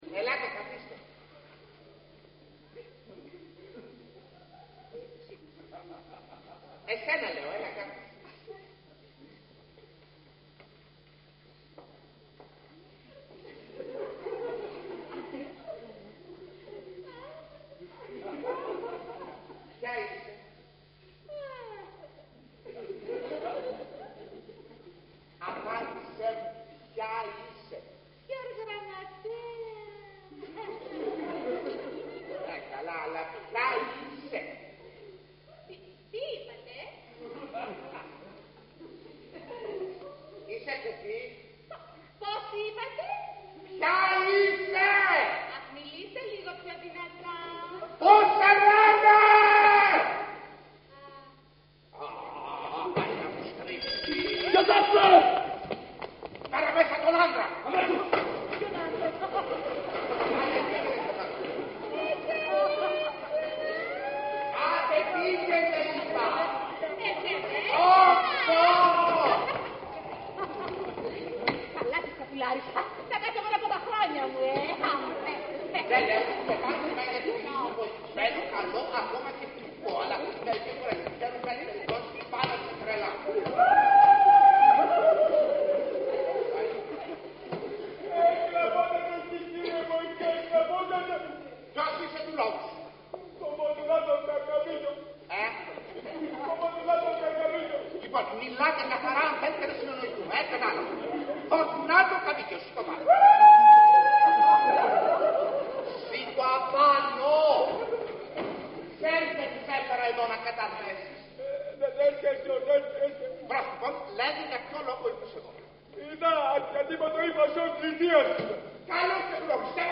Ηχογράφηση Παράστασης